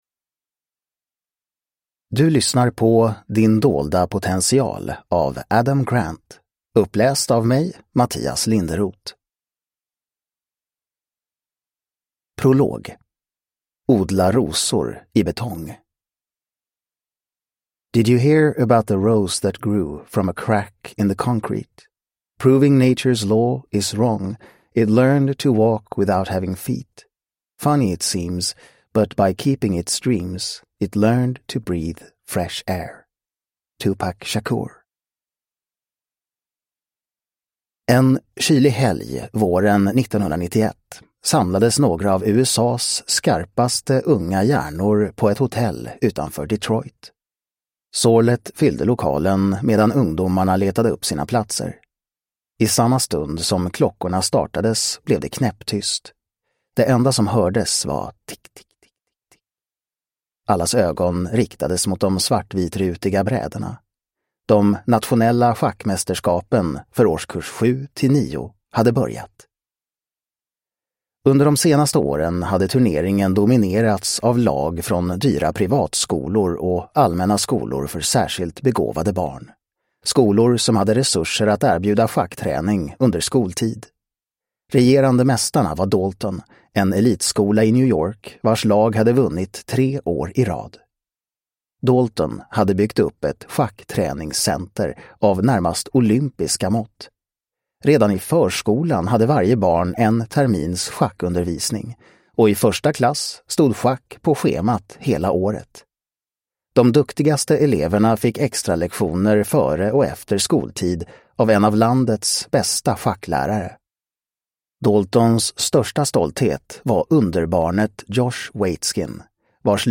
Din dolda potential: Konsten att nå nya höjder – Ljudbok